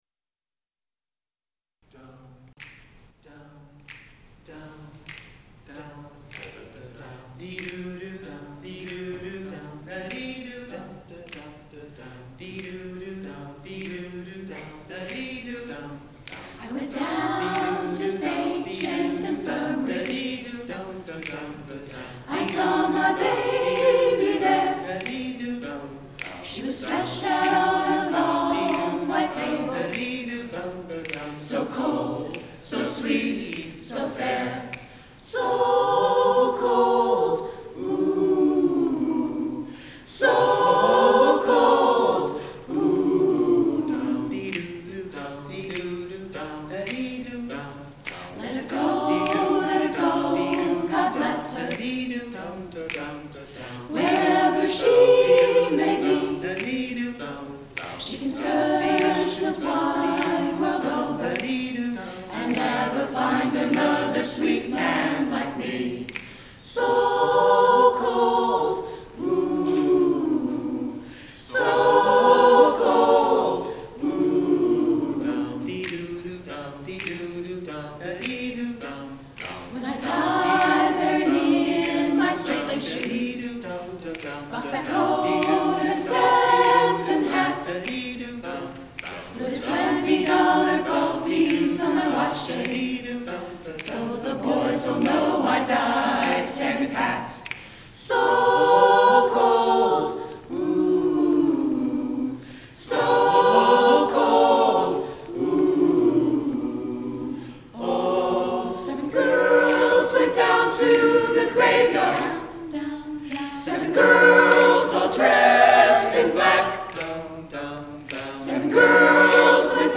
St. James Infirmary (SATB a cappella)
In 2012 I wrote an SATB a cappella arrangement of the old jazz standard St. James Infirmary.
here is a recording of the JUUL Tones a cappella group singing the song on 20 October 2013.